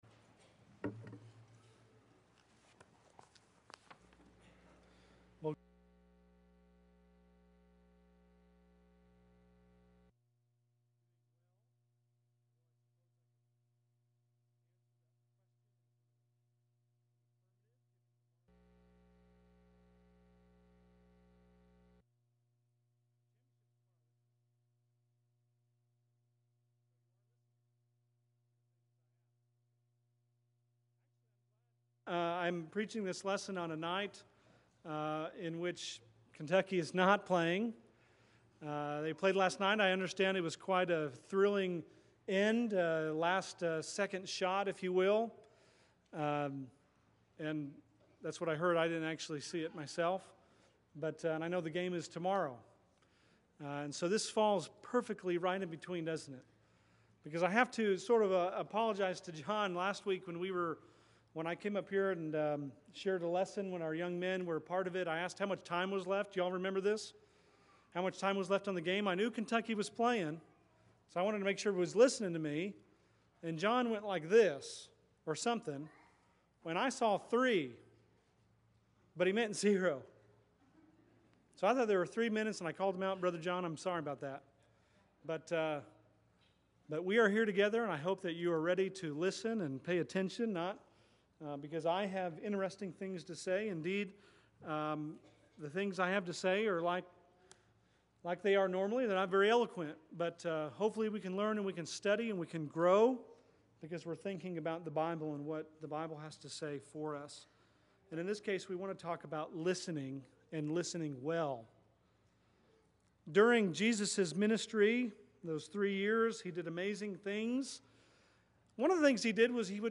Bible Text: Matthew 13:9 | Preacher